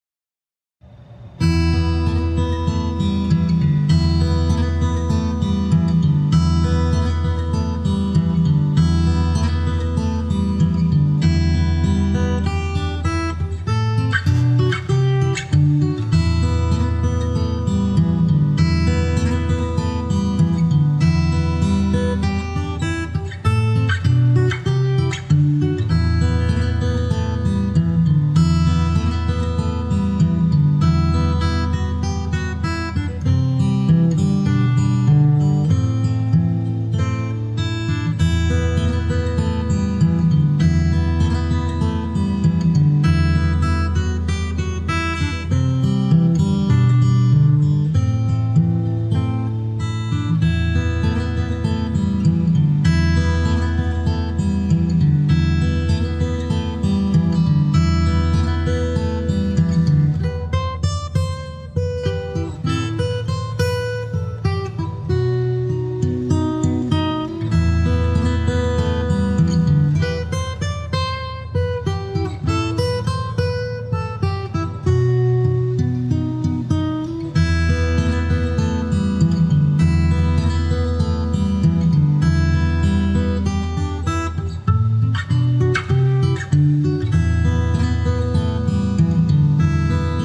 Guitar Instrumental